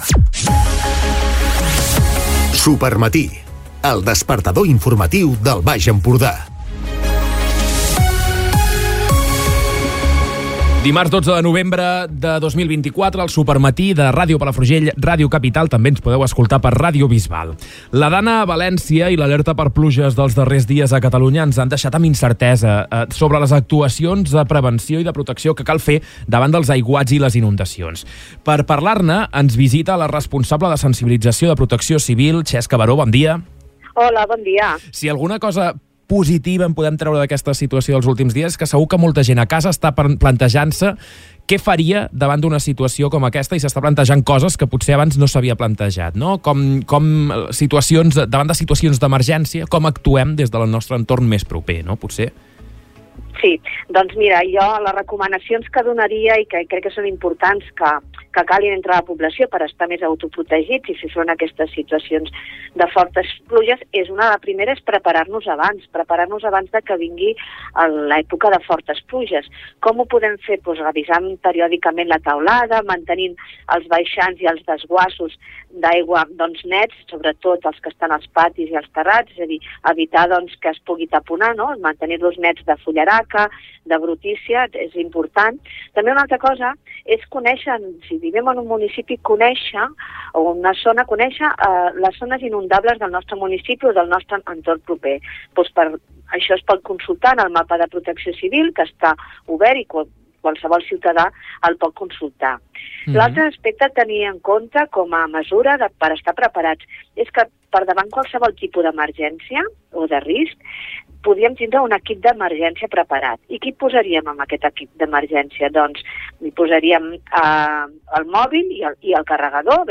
entrevista_protecciocivil.mp3